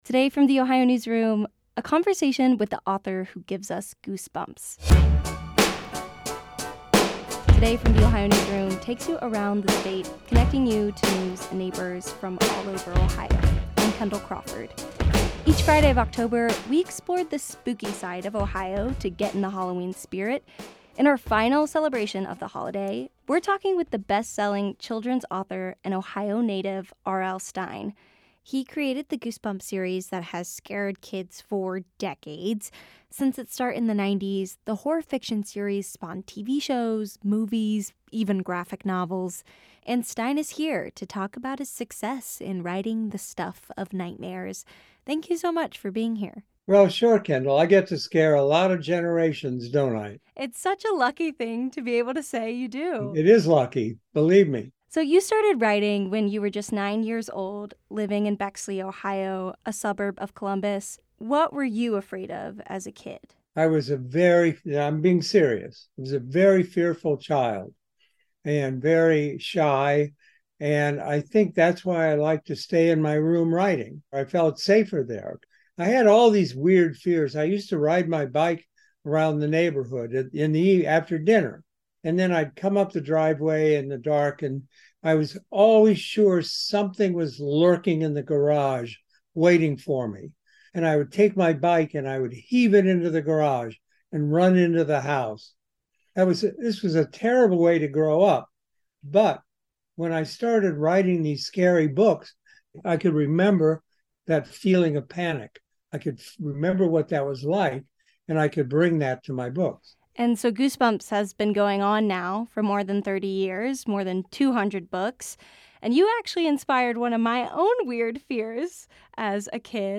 This interview has been edited for brevity and clarity.